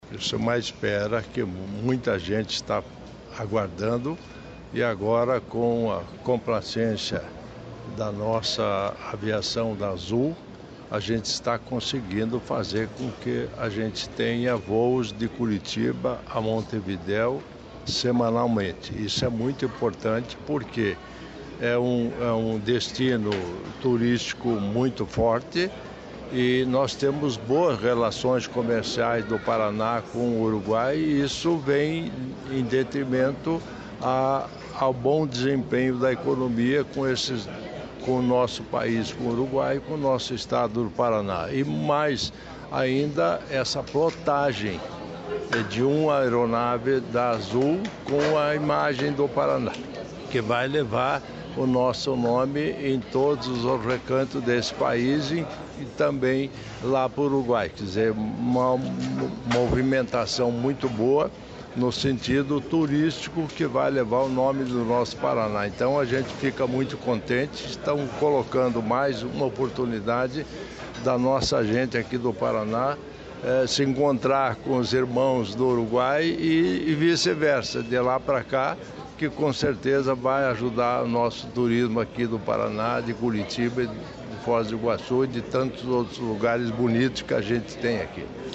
Sonora do vice-governador, Darci Piana, sobre o voo de Curitiba para Montevidéu